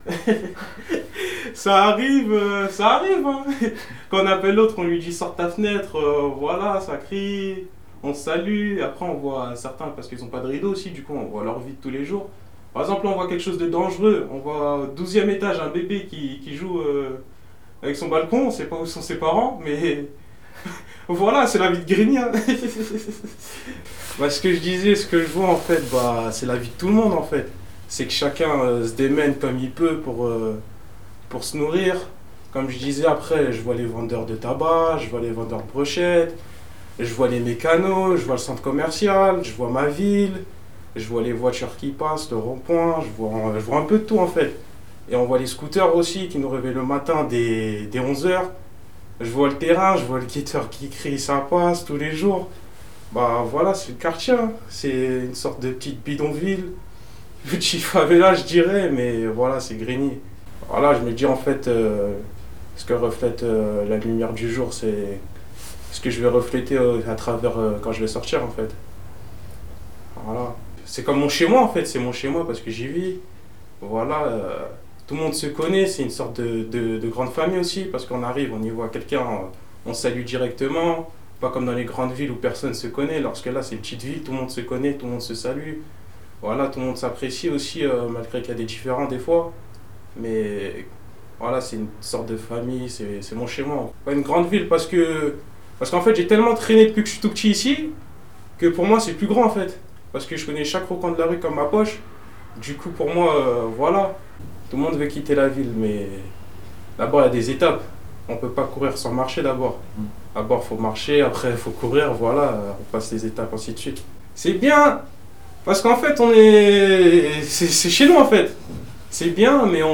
Une projection d'étape que vous pouvez visionner en ligne, mais aussi, en bonus, des échantillons sonores des rencontres à la Grande Borne…